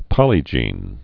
(pŏlē-jēn)